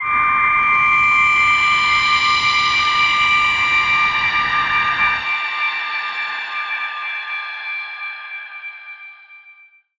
G_Crystal-D8-mf.wav